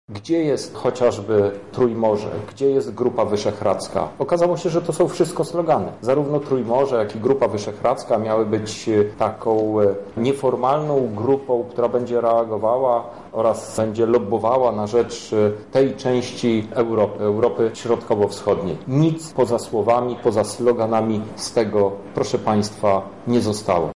Swoje rozczarowanie działaniami polskich rządzących na konferencji prasowej przedstawił europoseł Krzysztof Hetman.
-mówi europoseł Krzysztof Hetman.